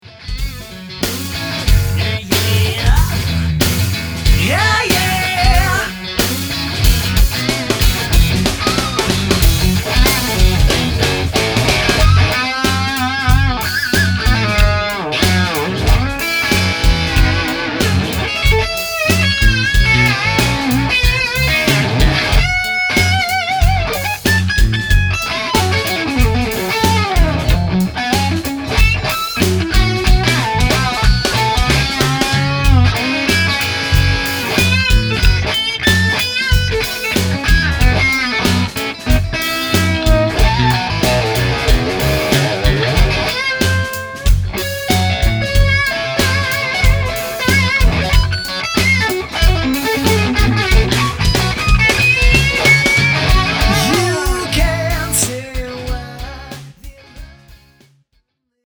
Equipment used audio clip #1: Fender Reissue Strat, Sebago Double Trouble amp, PRX150-DAG attenuator ("E" Step Attenuation level), Marshall 4x12 cabinet equipped with Celestion G12H Heritage 30 watt speakers (55Hz) cones. SM57 and SM58 - side mic'd to speaker center 4 inches out.